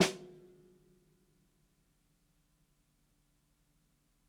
ROOMY_SNARE_SOFT_2.wav